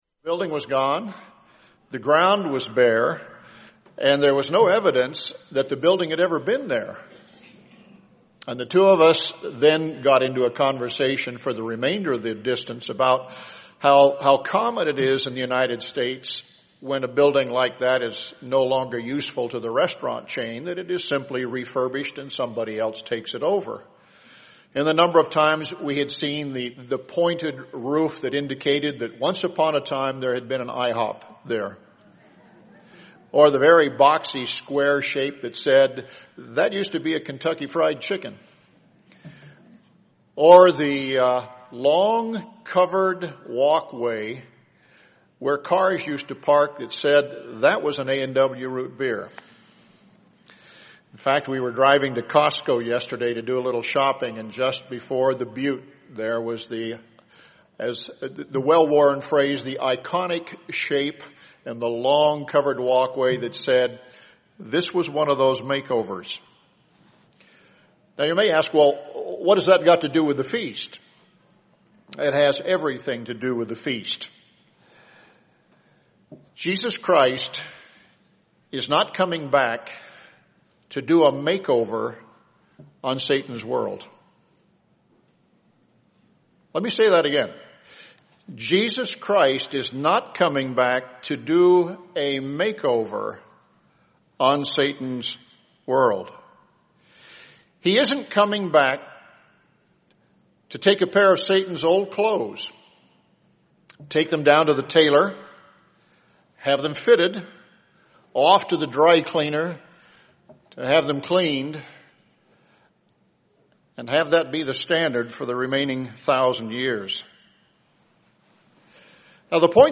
This sermon was given at the Bend, Oregon 2013 Feast site.